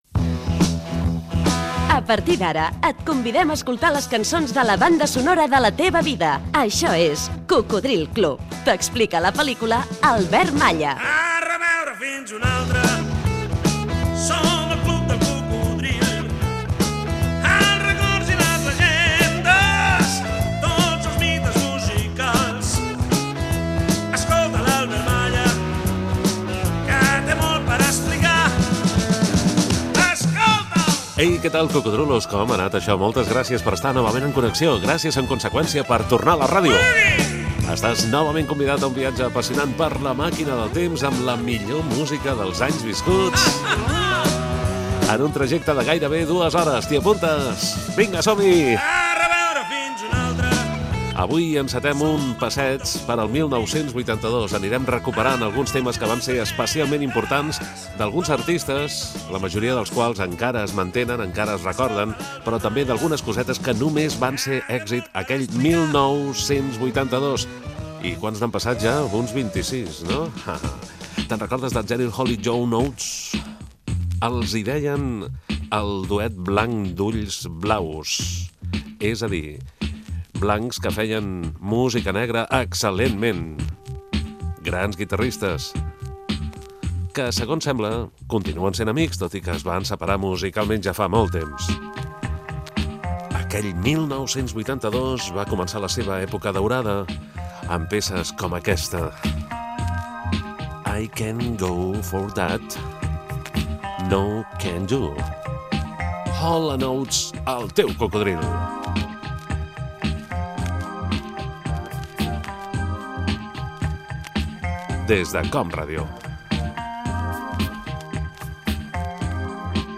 Careta del programa i presentació
Musical